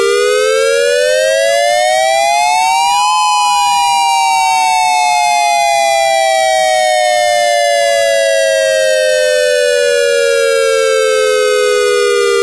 Evacuation Siren
Wail.mp3